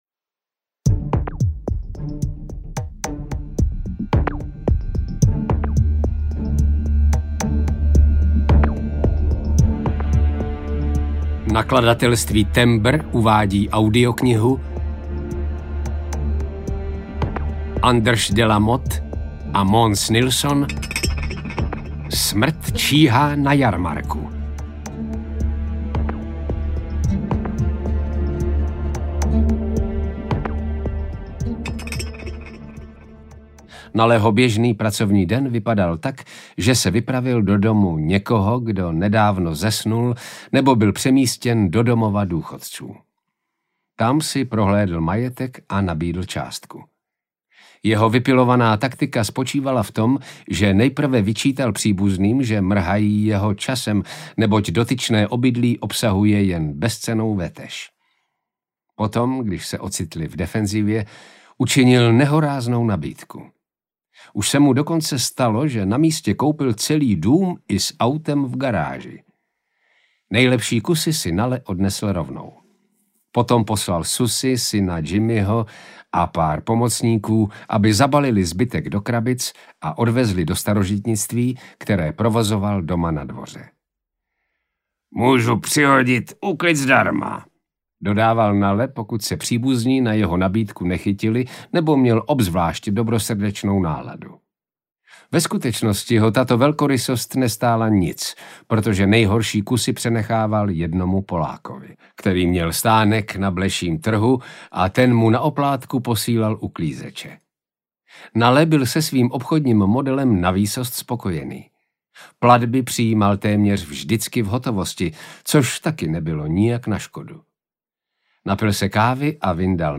Smrt číhá na jarmarku audiokniha
Ukázka z knihy